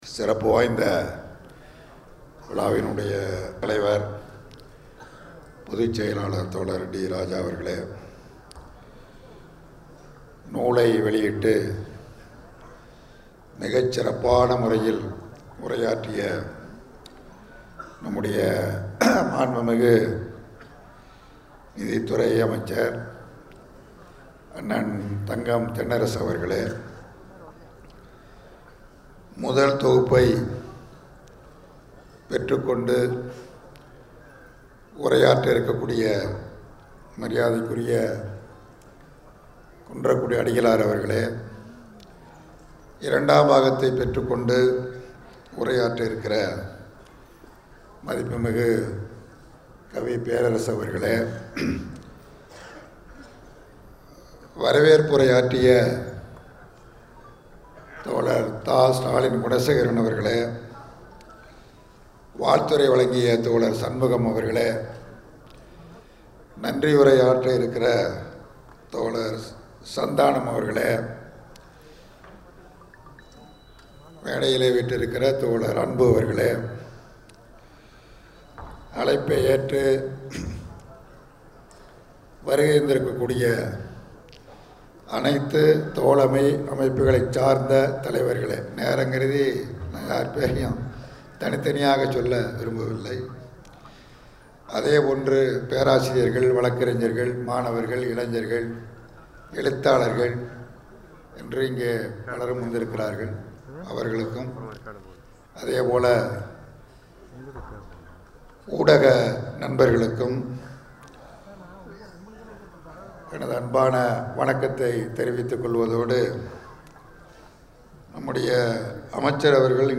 ‘காலம் தோறும் கம்யூனிஸ்டுகள்’ நூல் வெளியீட்டு விழாவில், இந்தியக் கம்யூனிஸ்ட் கட்சியின் மாநிலச் செயலாளர் இரா.முத்தரசன் வாழ்த்தி உரை நிகழ்த்தினார்.